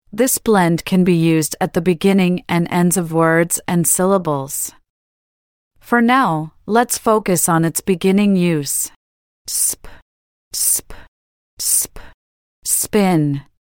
SP-spin-lesson-AI.mp3